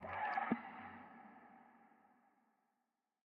Minecraft Version Minecraft Version snapshot Latest Release | Latest Snapshot snapshot / assets / minecraft / sounds / block / dried_ghast / ambient_water2.ogg Compare With Compare With Latest Release | Latest Snapshot
ambient_water2.ogg